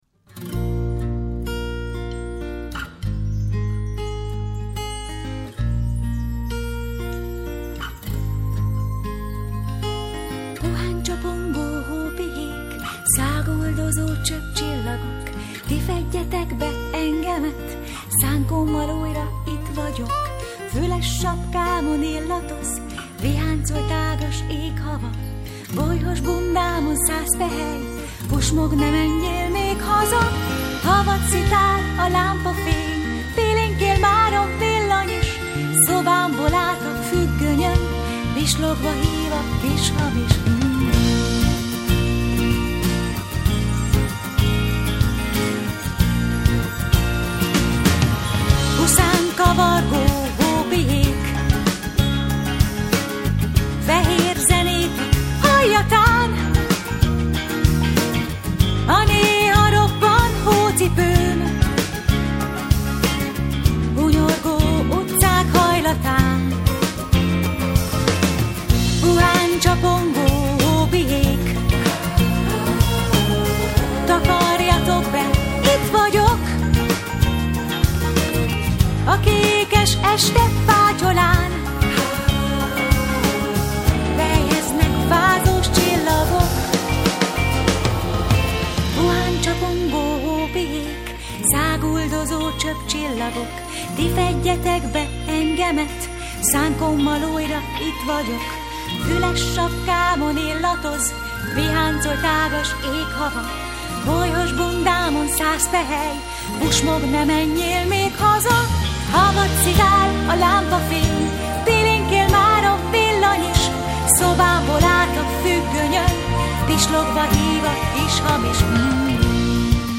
versek dalolva!